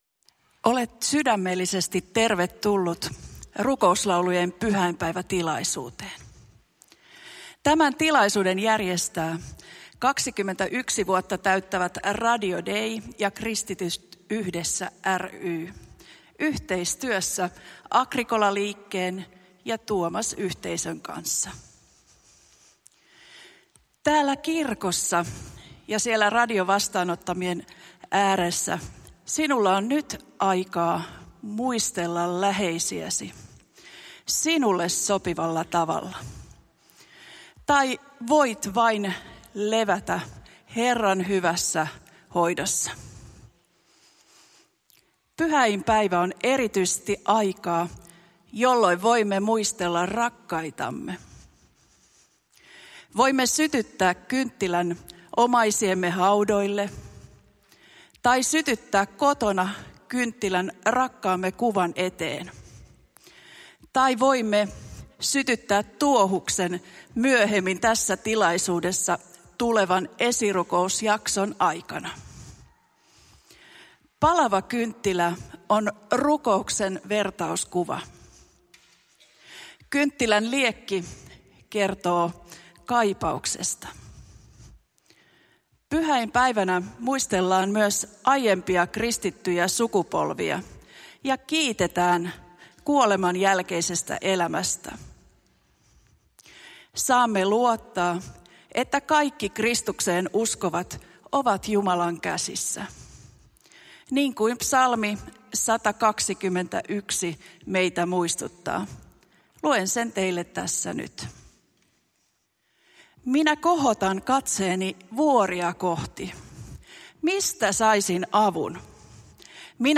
Järjestimme sen kunniaksi rukouslaulujen täyteisen pyhäinpäivän tapahtuman Helsingin Mikael Agricolan kirkossa. Tapahtumassa muisteltiin läheisiä ja rukoiltiin yhdessä. Tilaisuuden aikana saimme nauttia koskettavista rukouslauluista Carmen Ensemblen ja Tuohuskuoron esittämänä.
Kuuntele ensimmäinen osa Rukouslaulujen pyhäinpäivä -konsertista: